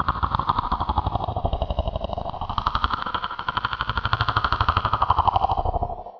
gargoyles.wav